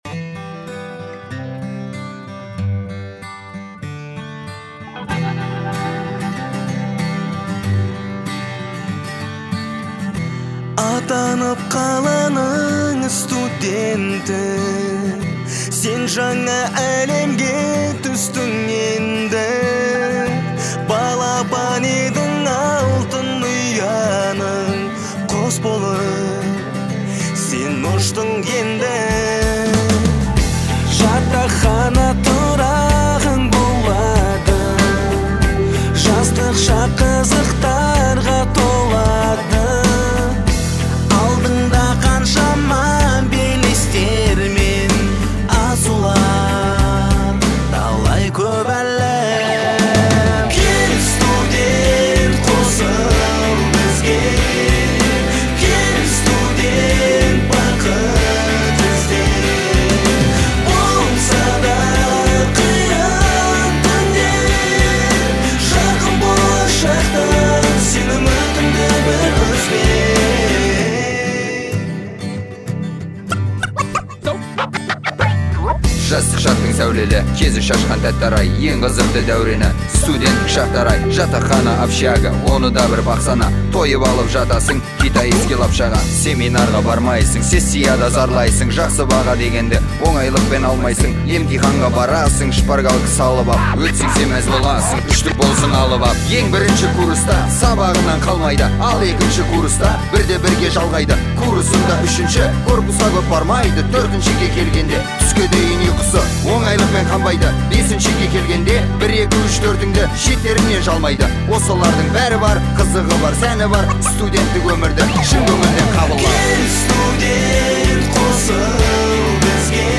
это динамичная и жизнеутверждающая песня в жанре поп-музыки